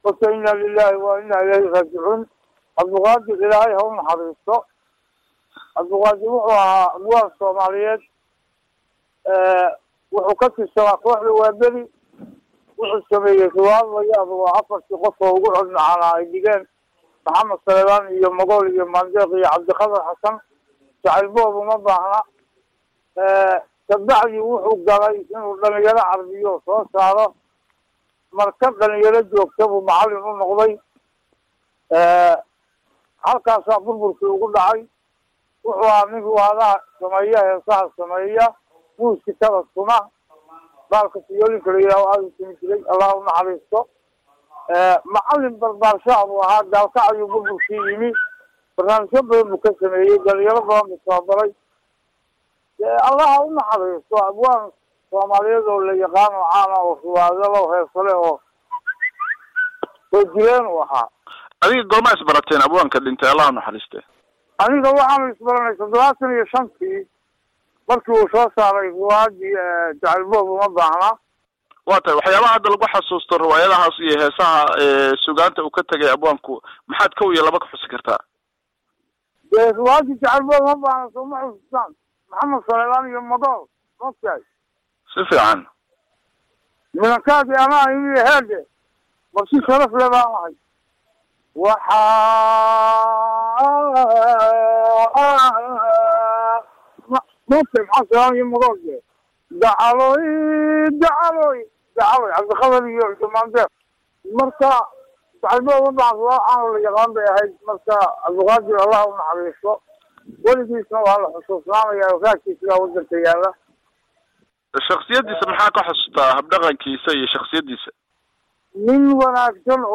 waraysi uu siiyay Radio Daljir